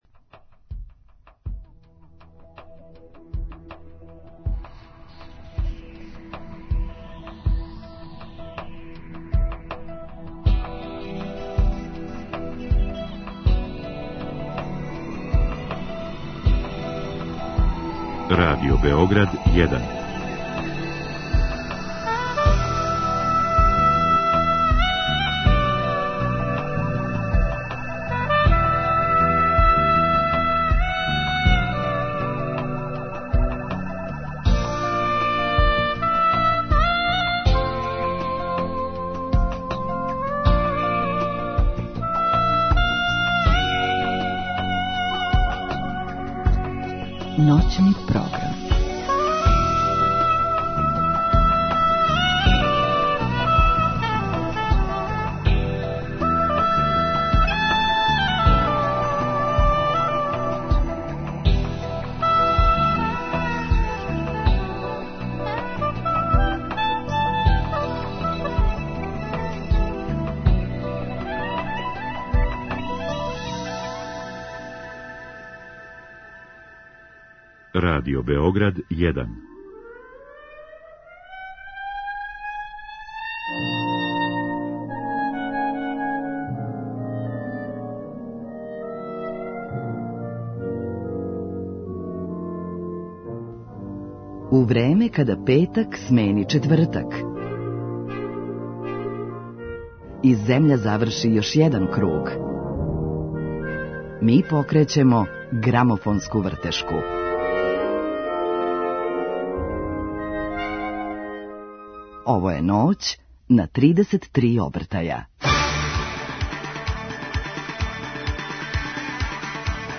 Гост: Александар Сања Илић, композитор